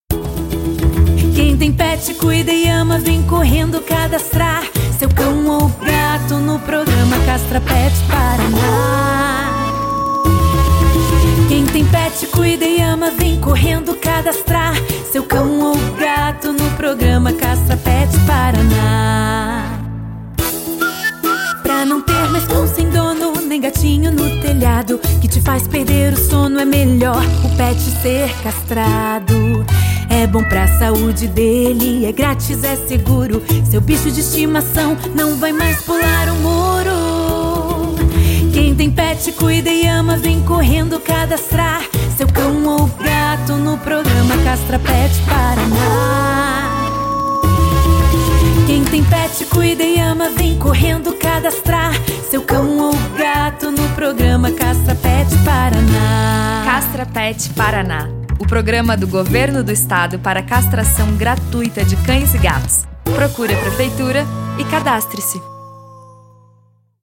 música oficial